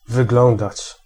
Ääntäminen
Tuntematon aksentti: IPA: /ˈsxɛi̯.nə(n)/